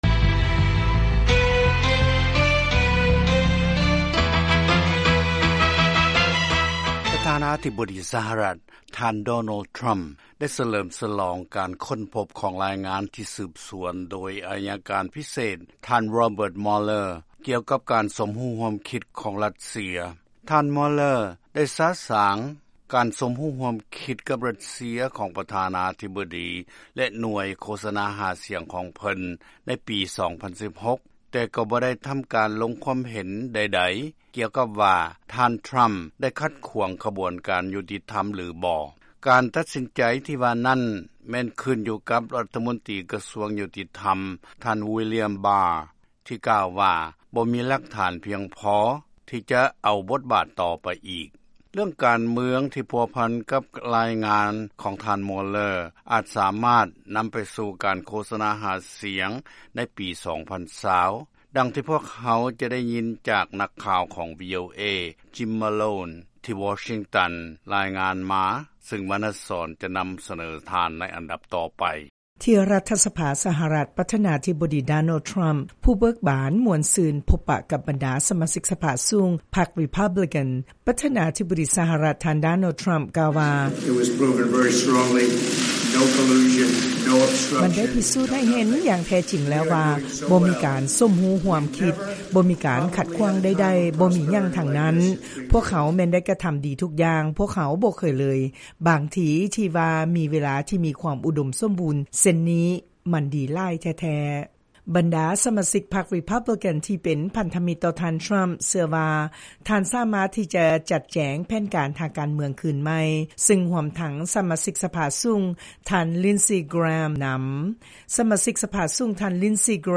ລາຍງານກ່ຽວກັບການກະຕຸ້ນທາງການເມືອງຂອງທ່ານທຣຳ ຫຼັງຈາກຜົນສະຫຼຸບຂອງລາຍງານຂອງໄອຍະການພິເສດ